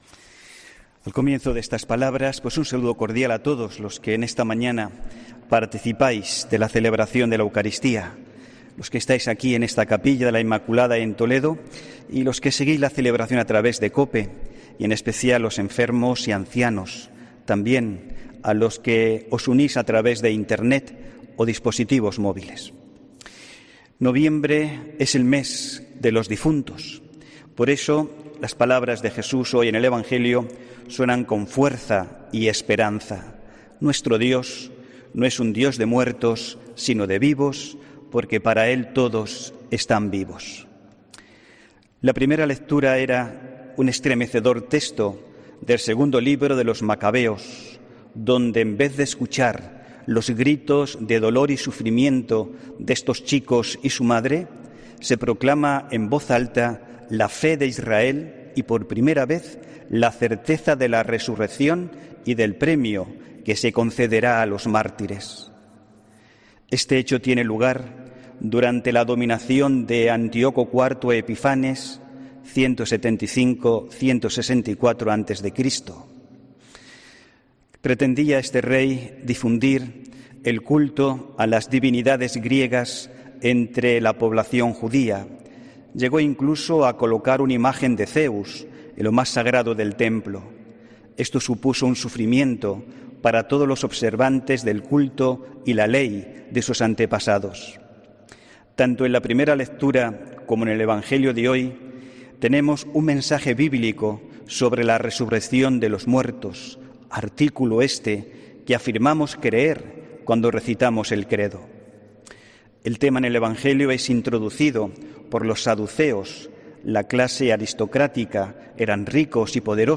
AUDIO: HOMILÍA 10 NOVIEMBRE